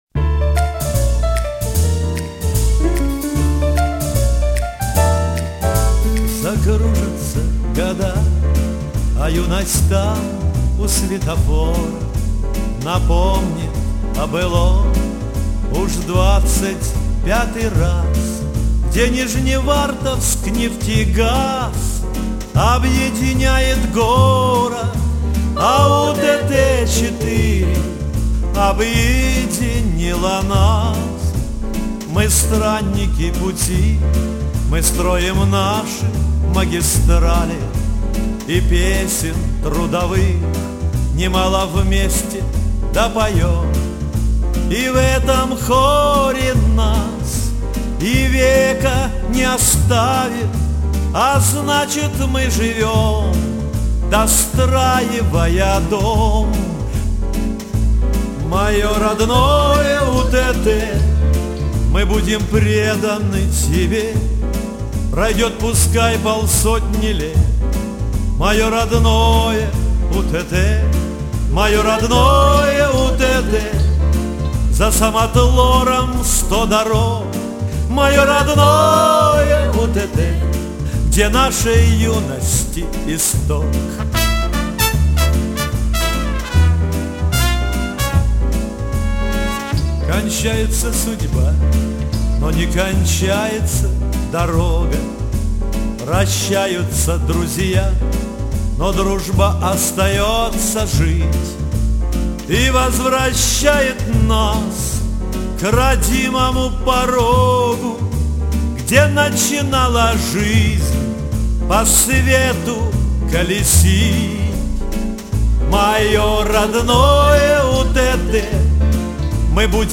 труба